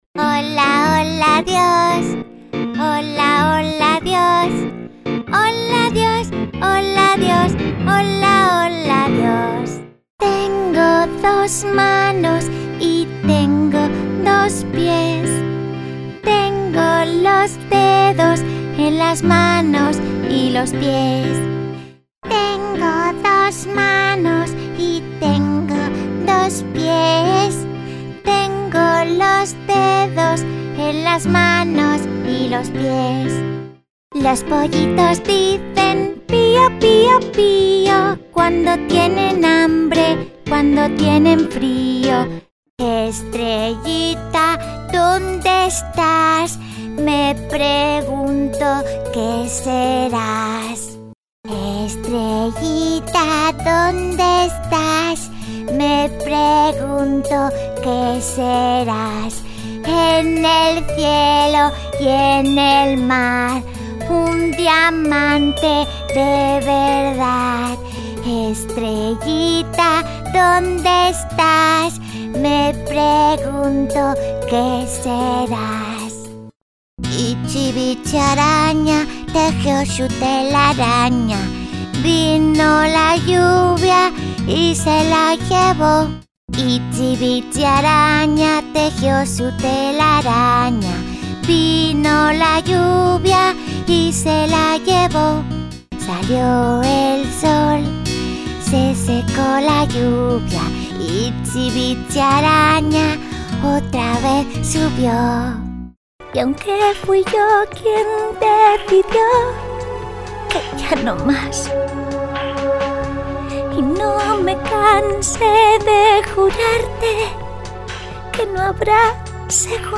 Actriz de doblaje y cantante con registro infantil, adolescente, adulto e incluso abuelitas.
Sprechprobe: Sonstiges (Muttersprache):
My voice is warm and clear for narrations, fun and young for commercials, professional and smooth for presentations.